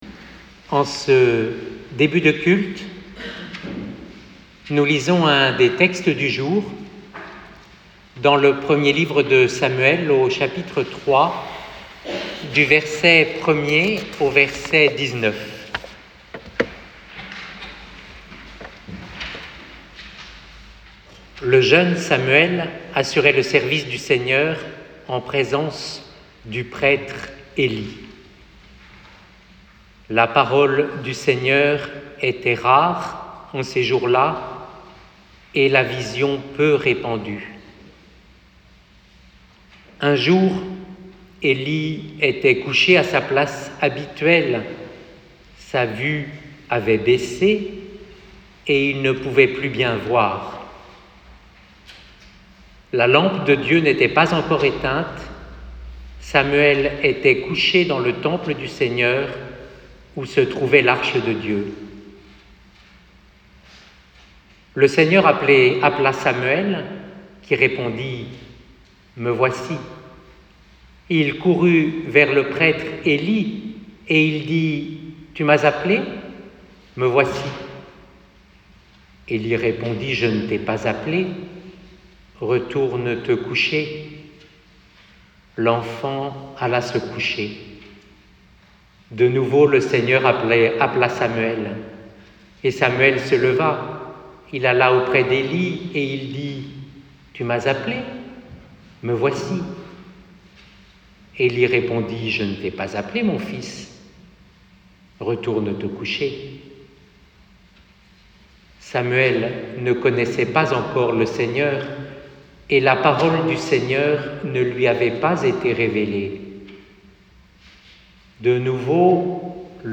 "MANIFESTEZ-VOUS" culte du 7 janvier 2024